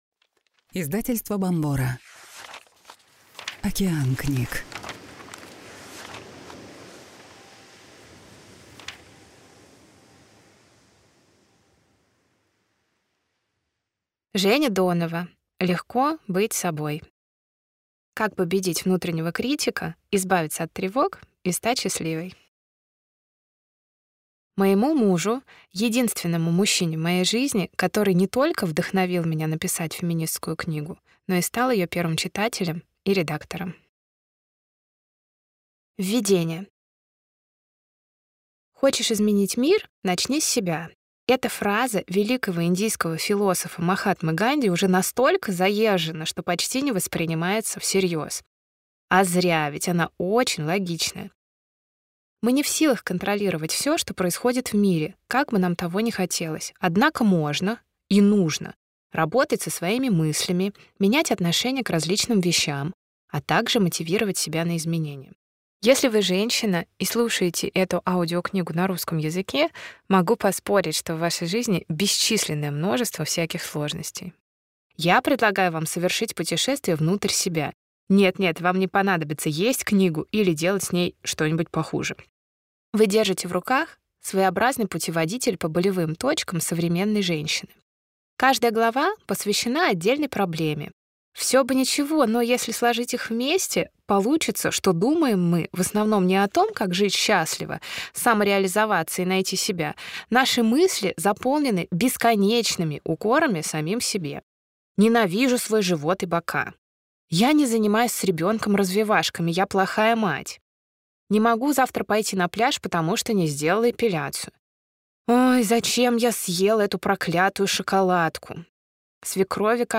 Аудиокнига Легко быть собой. Как победить внутреннего критика, избавиться от тревог и стать счастливой | Библиотека аудиокниг